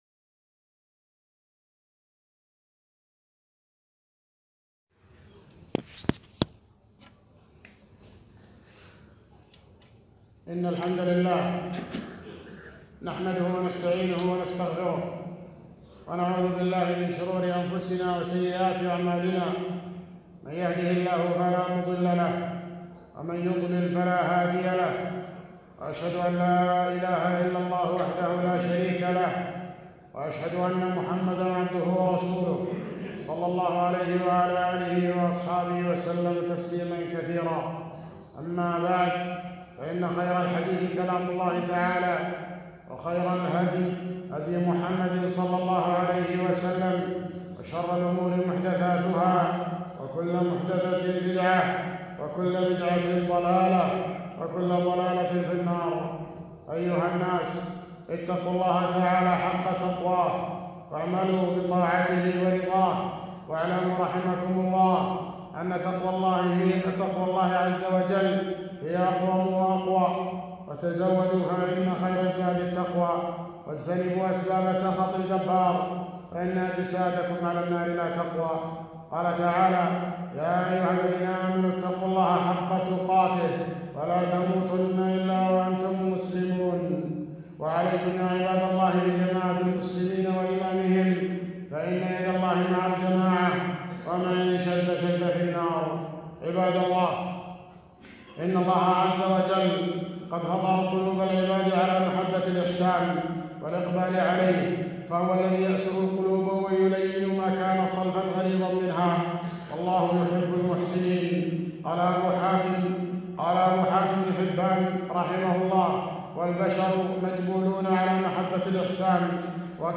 خطبة - تهادوا تحابوا